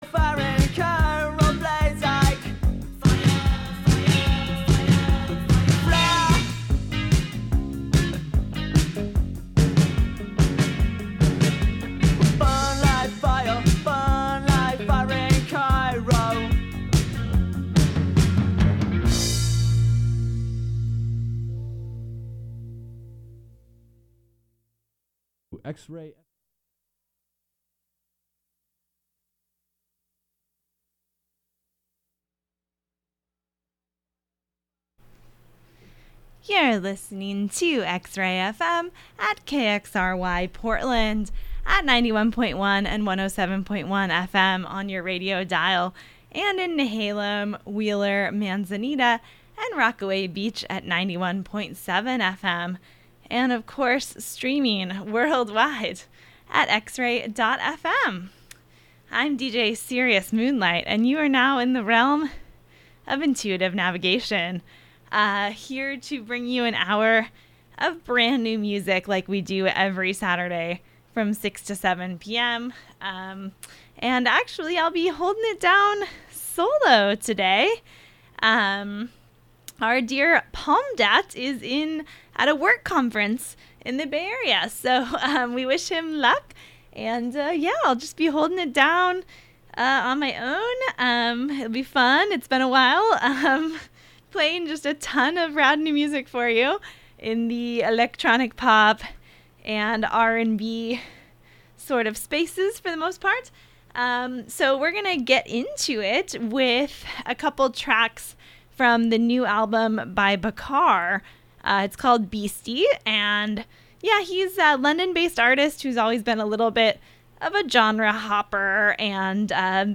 The latest in electronic pop, R&B, and other soulful music.